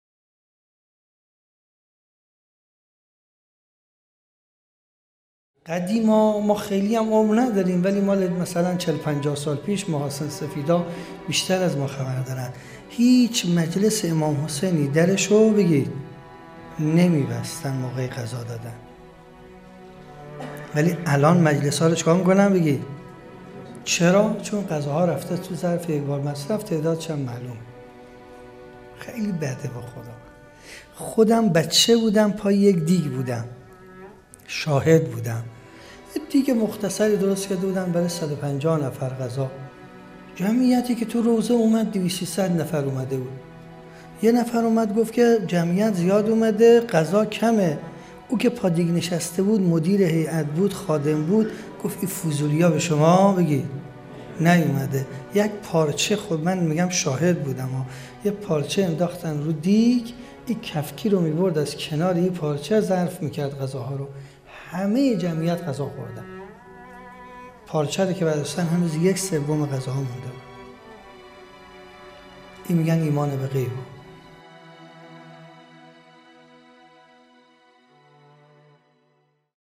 چهارمین همایش ملی هیأت‌های محوری و برگزیده کشور | شهر مقدس قم - مجتمع یاوران مهدی (عج)